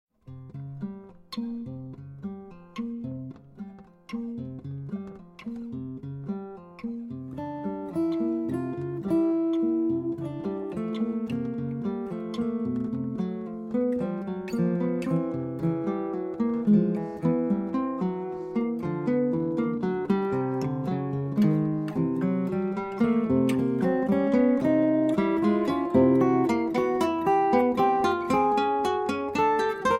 Two Master Guitarists + 18 tracks = Acoustic Improv Heaven